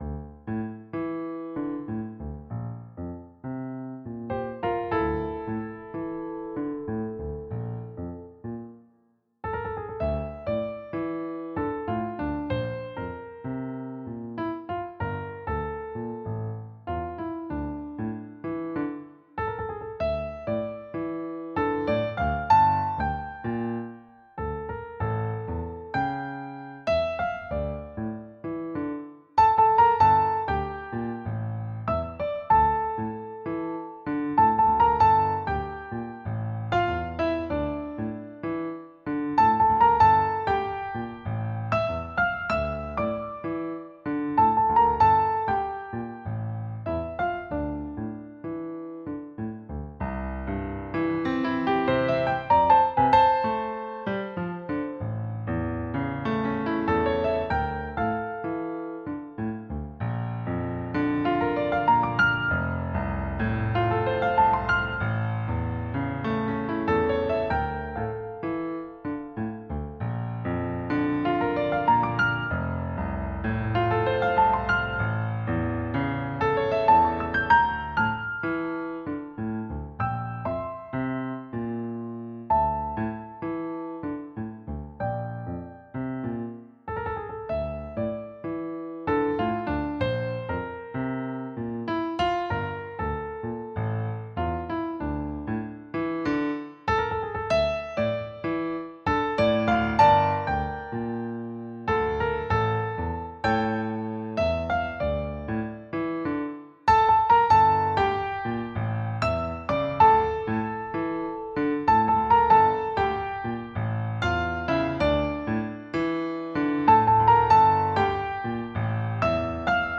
• Key: F Major
• Time signature: 3/4